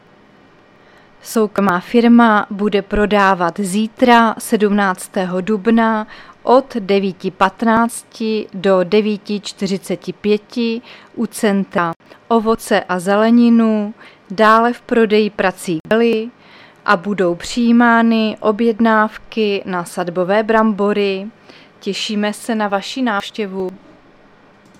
Záznam hlášení místního rozhlasu 16.4.2024
Zařazení: Rozhlas